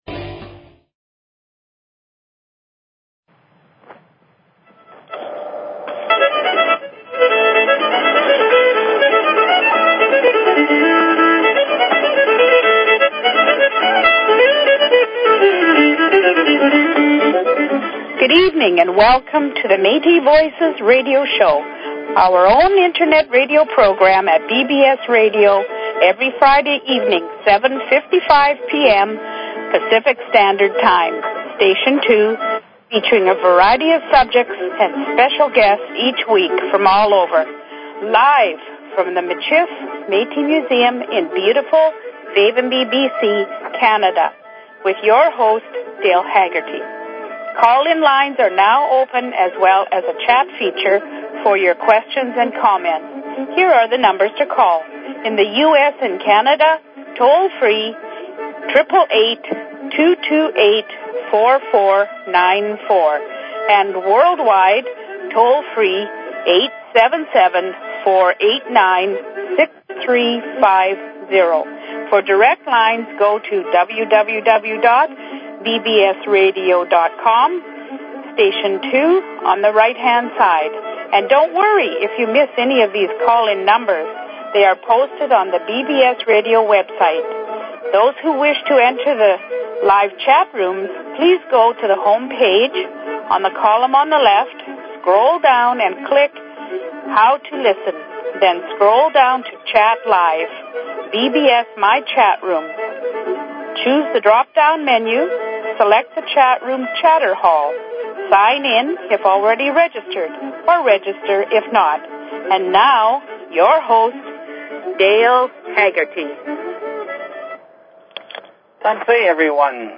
Talk Show Episode, Audio Podcast, Metis_Voices_Radio and Courtesy of BBS Radio on , show guests , about , categorized as
LIVE FROM VAVENBY, BC WE HOPE YOU WILL TUNE IN....